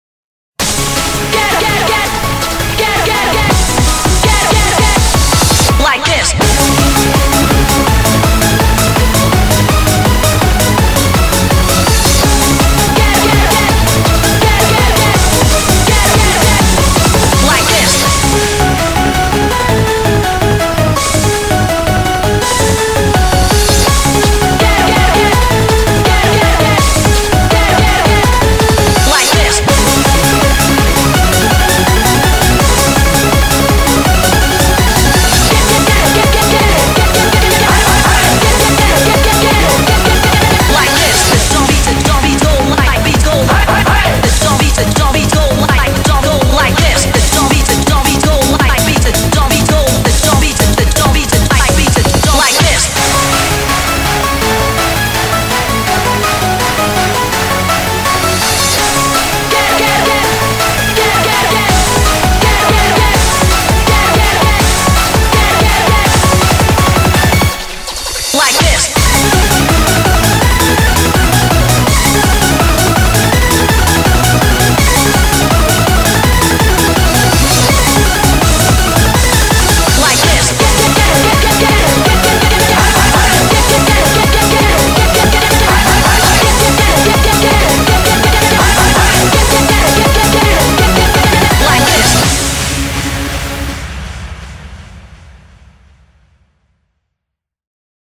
BPM165